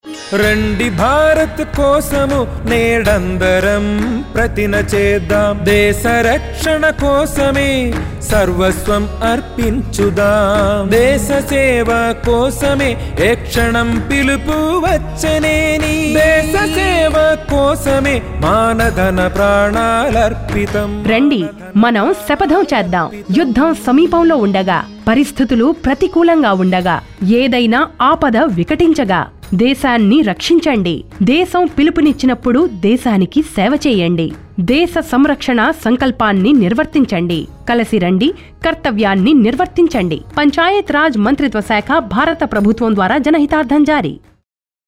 226 Fundamental Duty 4th Fundamental Duty Defend the country and render national services when called upon Radio Jingle Telugu